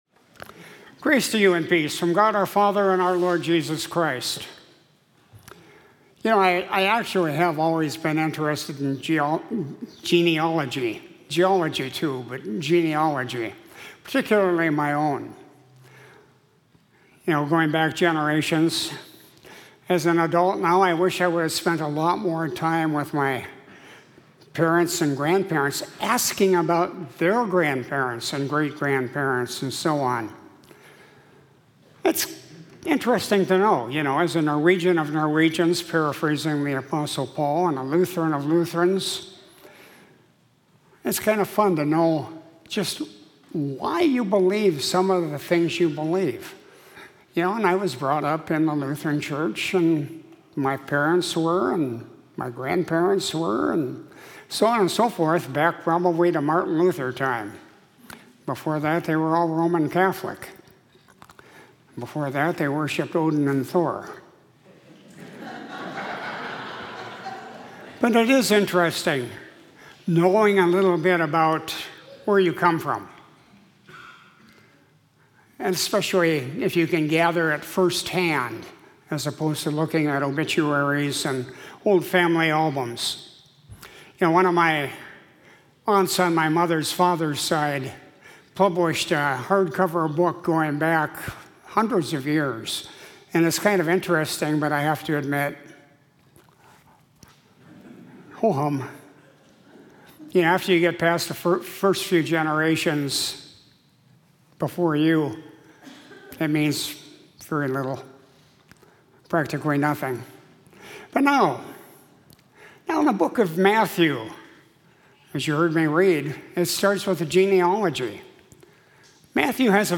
Sermon for Sunday, January 1, 2023